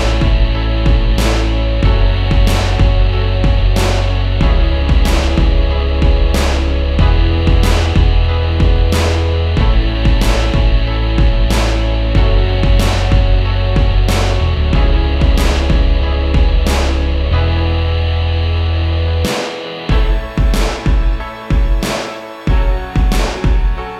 no Backing Vocals R'n'B / Hip Hop 3:50 Buy £1.50